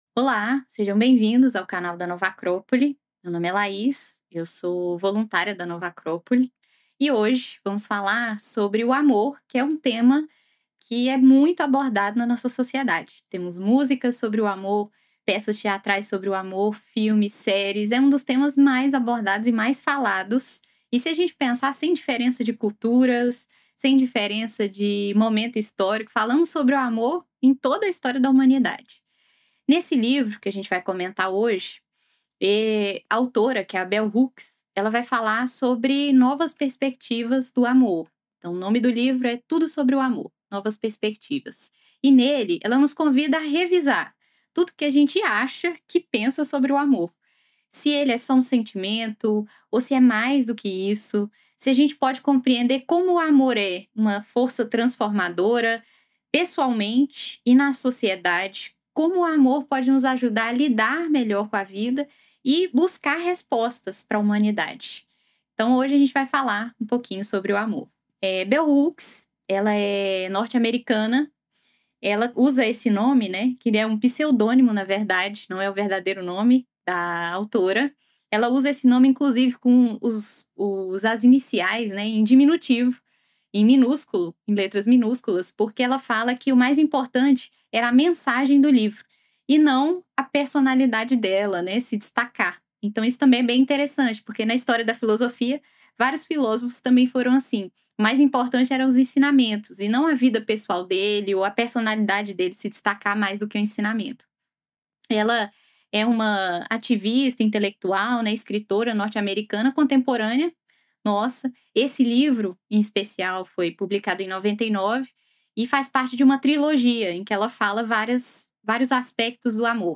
Uma palestra que convida ao autoconhecimento, ao resgate da alma e à construção de relações mais humanas e verdadeiras.